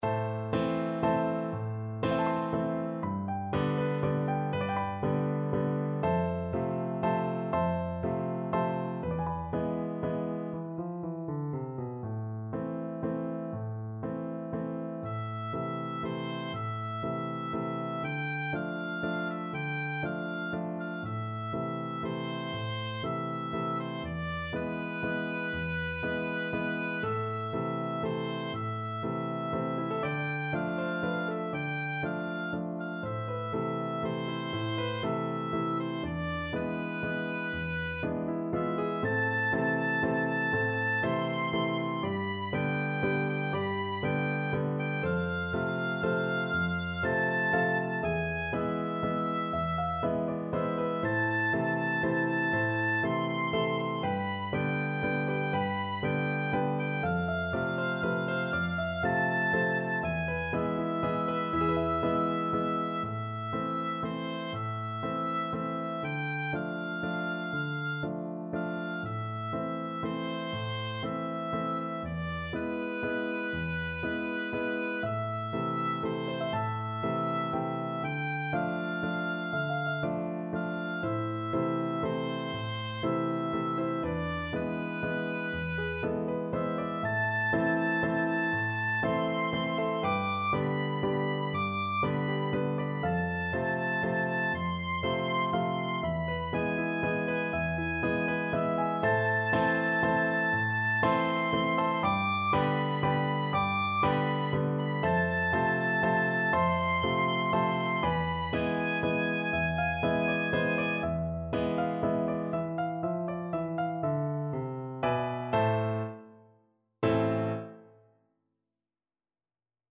Traditional Trad. La Llorona (Mexican Traditional Song) Oboe version
Oboe
A minor (Sounding Pitch) (View more A minor Music for Oboe )
Slow Waltz .=40
3/4 (View more 3/4 Music)